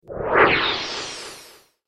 جلوه های صوتی
دانلود صدای کشتی 2 از ساعد نیوز با لینک مستقیم و کیفیت بالا